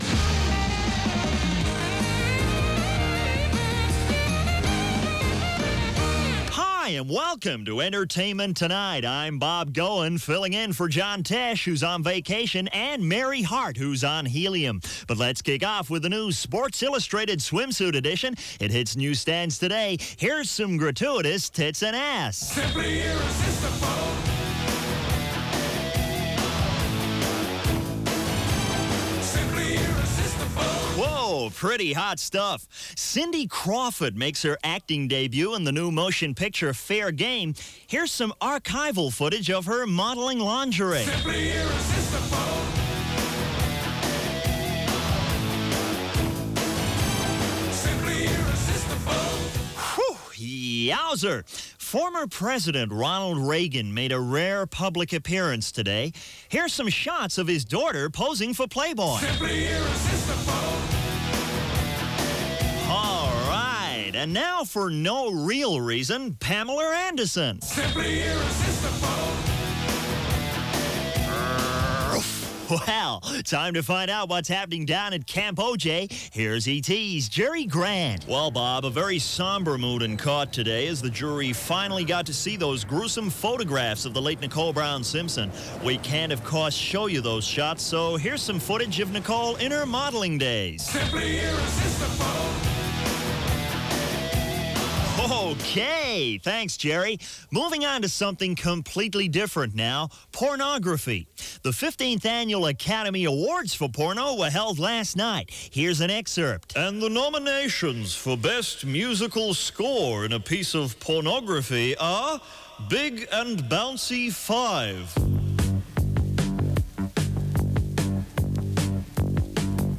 Each episode of Martin/Molloy would consist of several prerecorded sketches, regularly satirising pop culture of the time and often using commercial music.